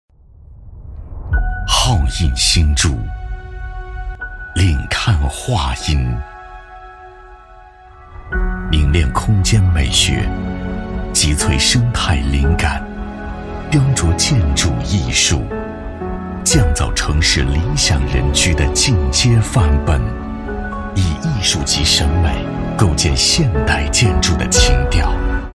A男15号
【地产】悦华府（高端）